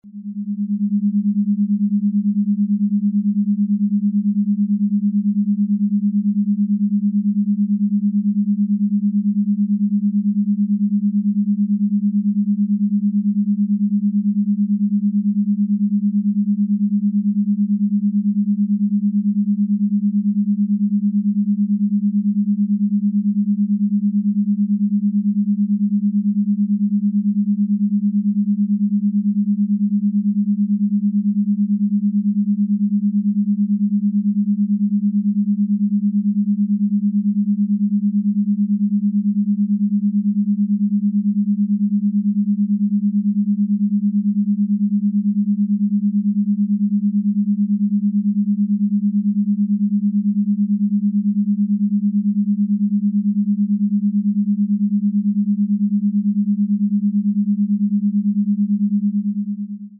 The audio frequency samples below were recorded with the app.
200 Hz & 209 Hz : 9 Hz beat frequency (Alpha)
Binaural beat.